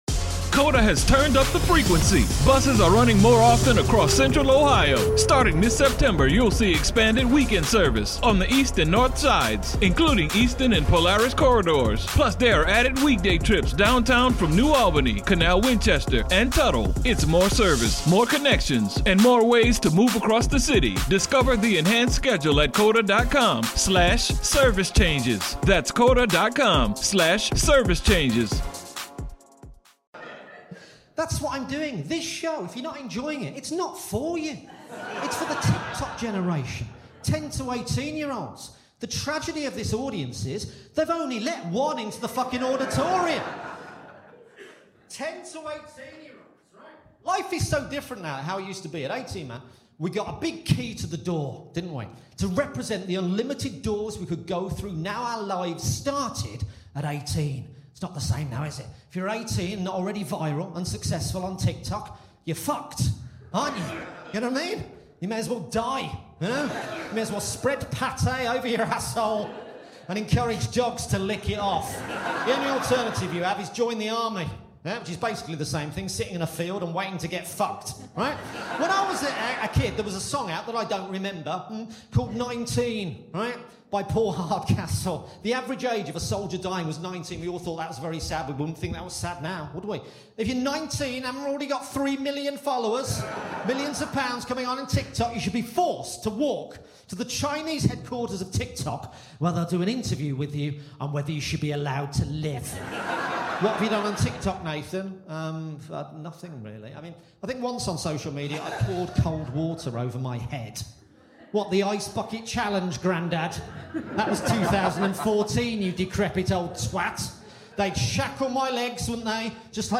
Recorded Live at Just The Tonic Edinburgh 2023.